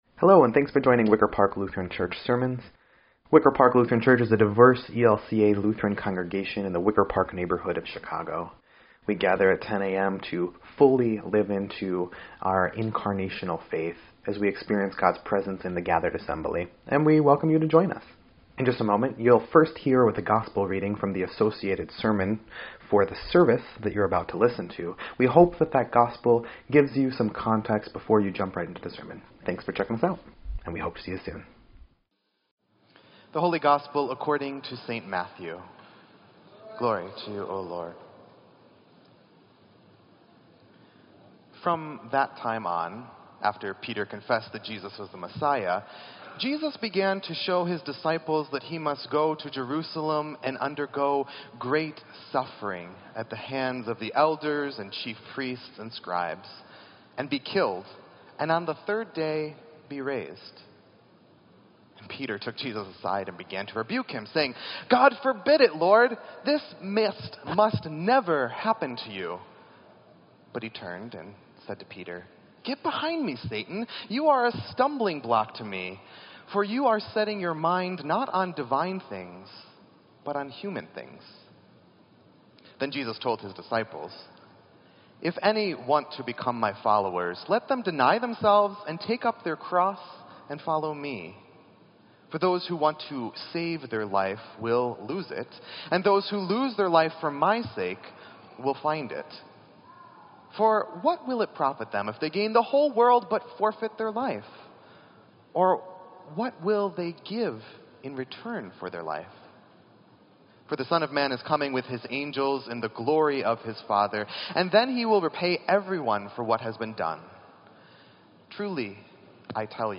Sermon_9_3_17_EDIT.mp3